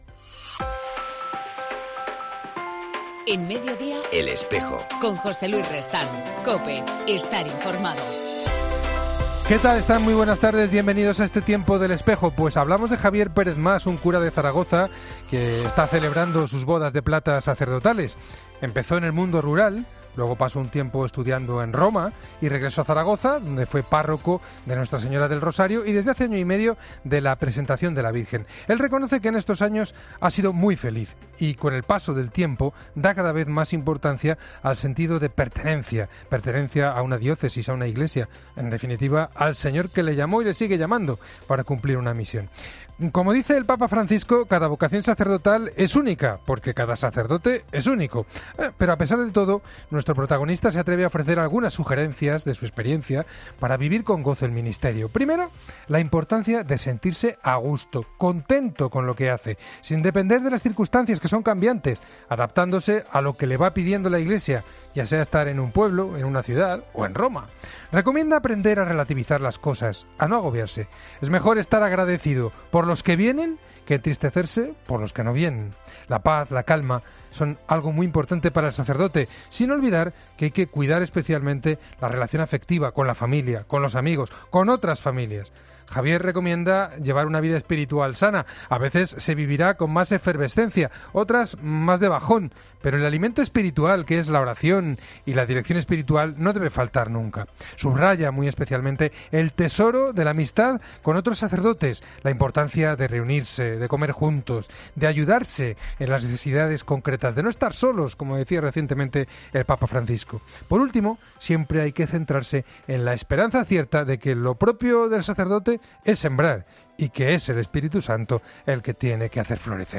En El Espejo del 19 de marzo entrvistamos a dos seminaristas de Canarias y Valencia